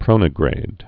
(prōnə-grād)